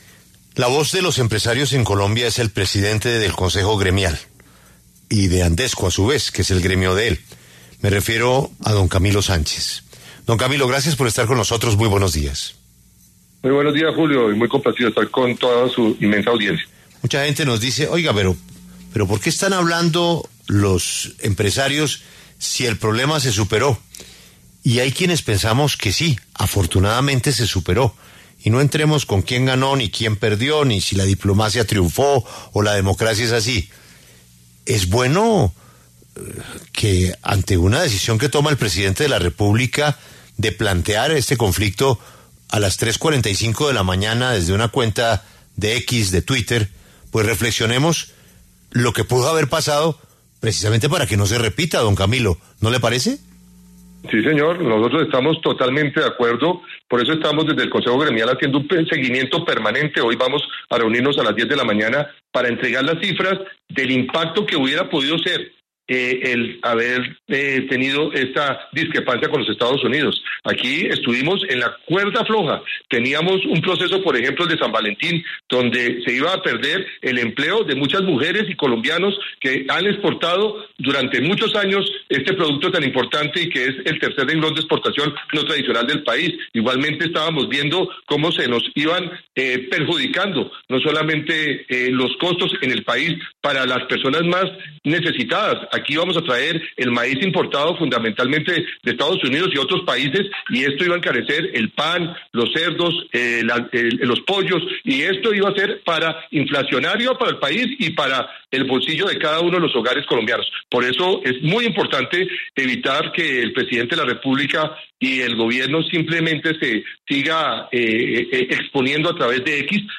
habló en La W sobre la crisis diplomática que se desató este domingo 26 de enero entre Colombia y Estados Unidos, luego de que el presidente Petro se negara a recibir dos aviones con colombianos deportados y en respuesta, Trump dictara fuertes sanciones contra Colombia como el aumento de los aranceles.